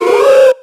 HOOTHOOT.ogg